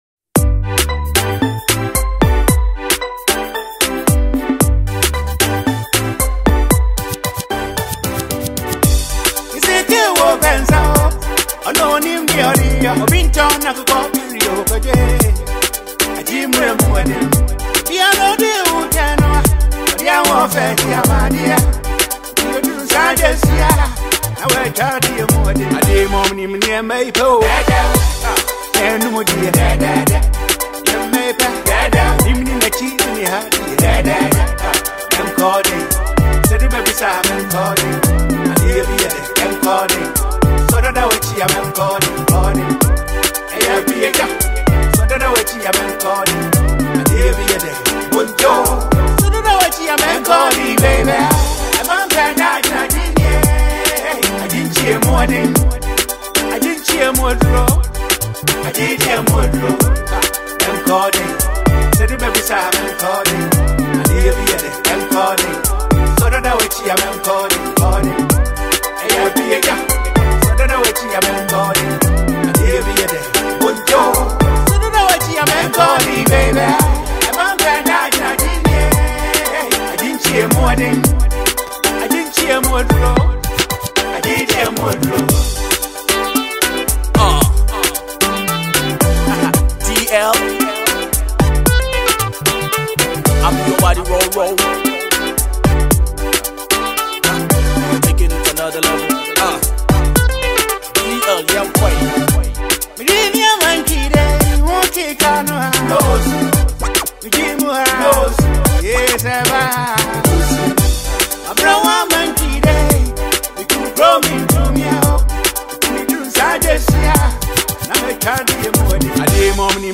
Ghana’s legendary highlife icon
• It delivers authentic Ghanaian highlife vibes.
vocal delivery is simply classic.